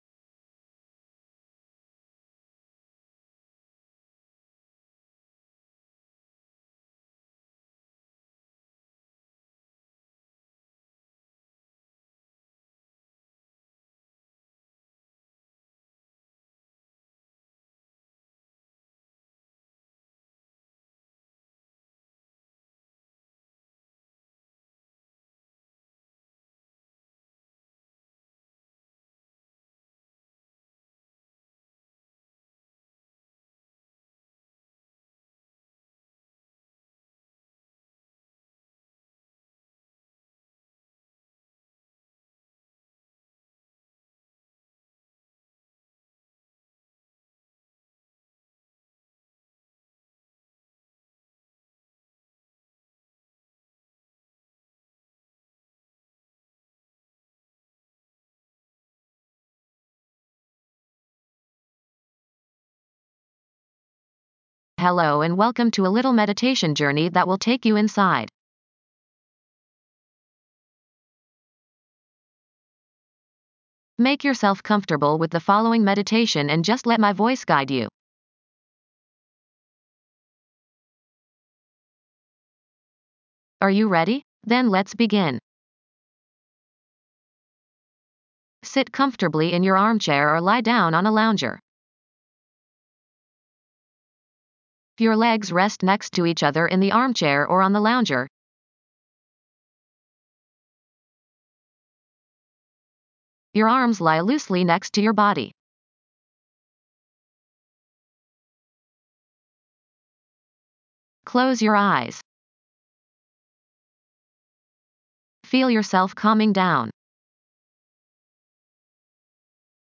Hold your concentration on this / those places while you let the music play over you.